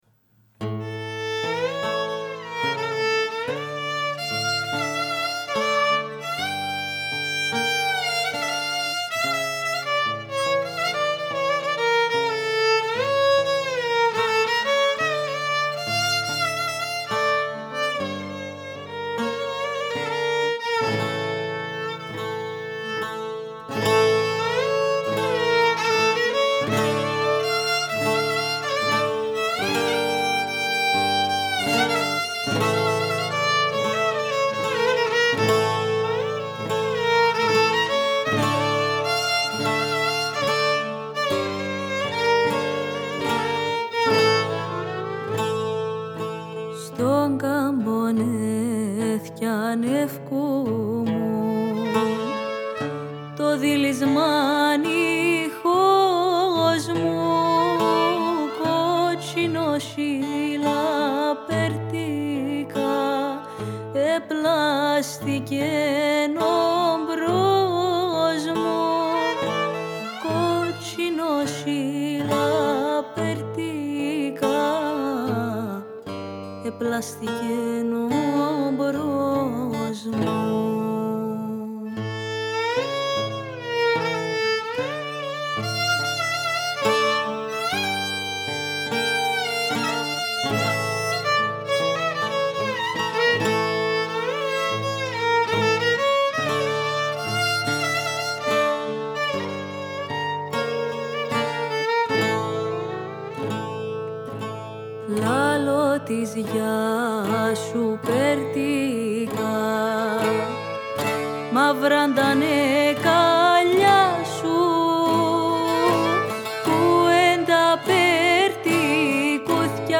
Η κυπριακή παραδοσιακή μουσική